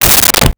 Car Door Closed 06
Car Door Closed 06.wav